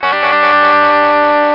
Guitar Br Th Sound Effect
Download a high-quality guitar br th sound effect.
guitar-br-th.mp3